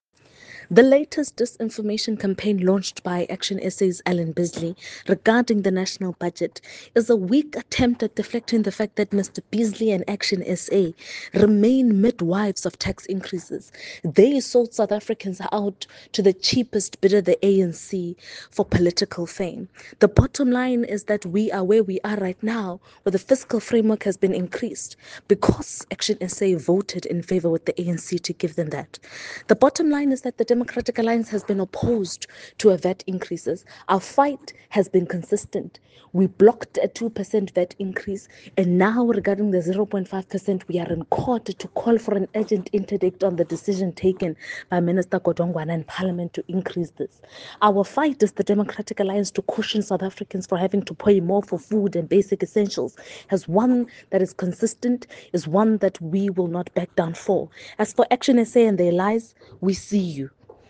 soundbite by Karabo Khakhau MP.